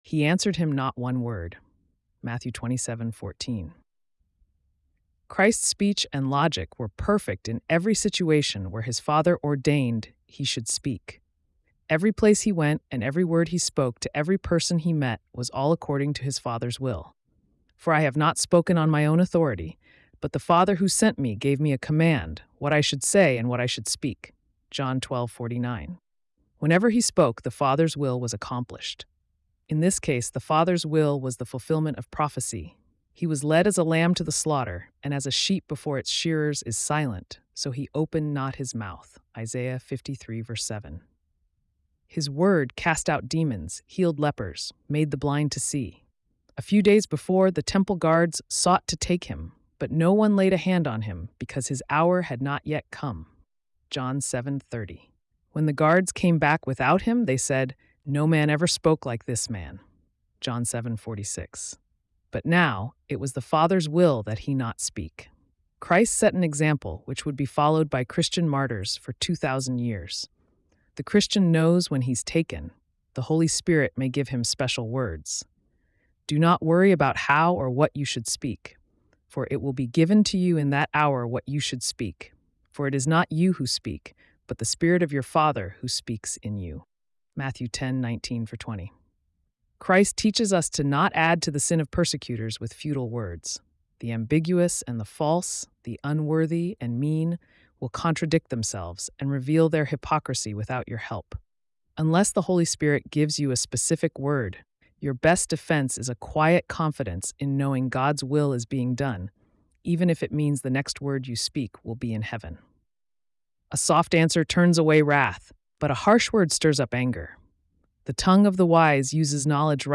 April 2 Morning Devotion